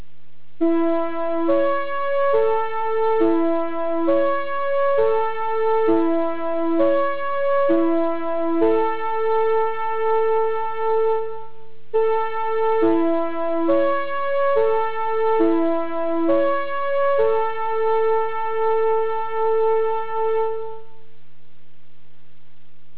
２０００系メロディーホーン